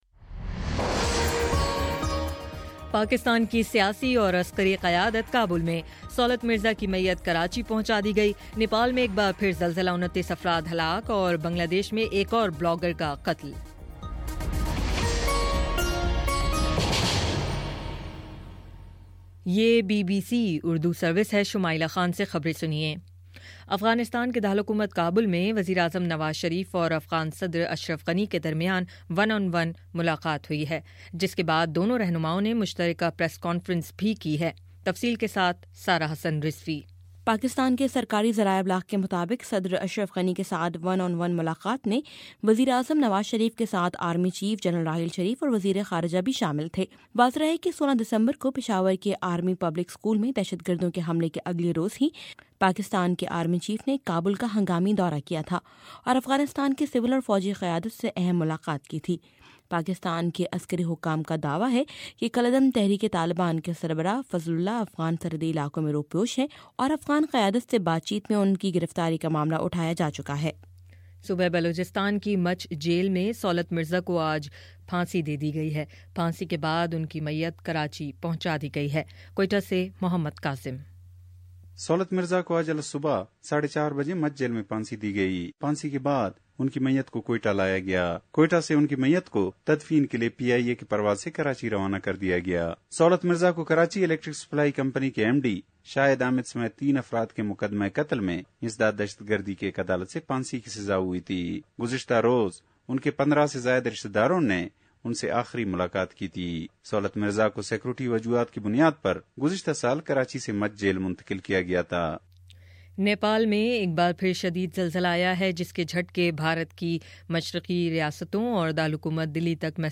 مئی12: شام پانچ بجے کا نیوز بُلیٹن